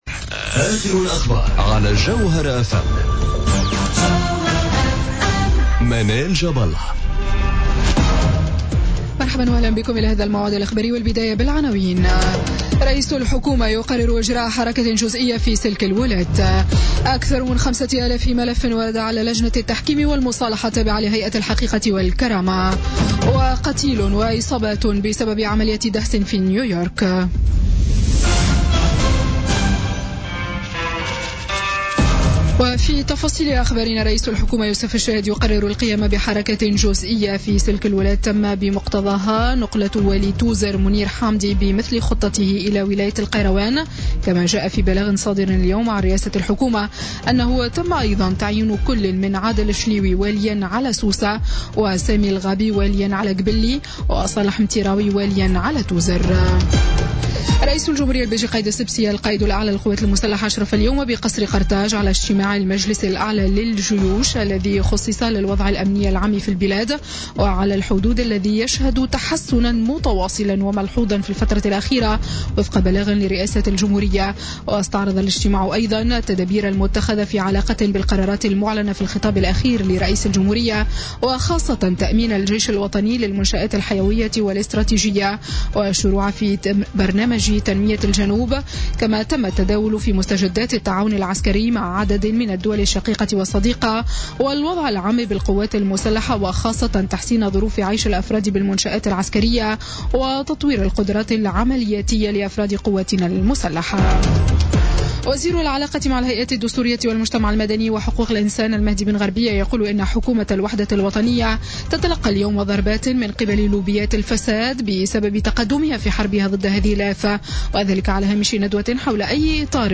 نشرة الأخبار السابعة مساء ليوم الخميس 18 ماي 2017